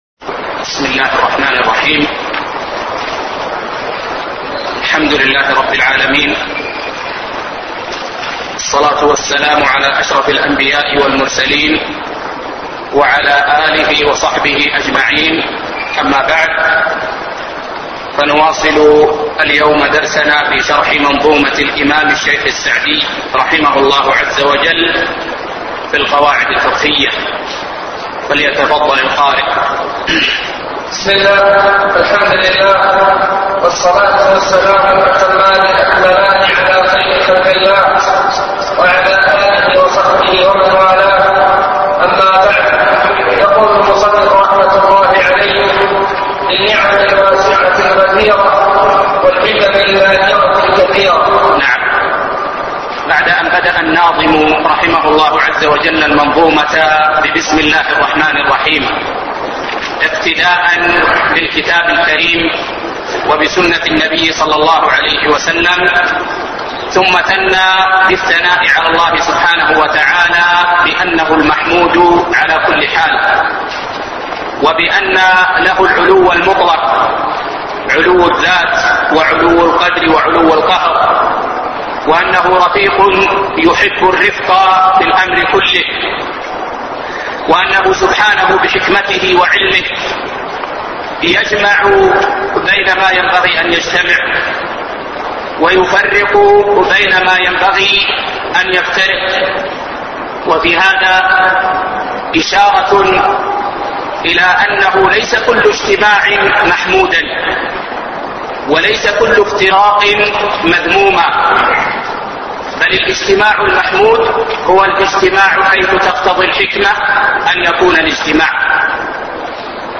الدرس الثاني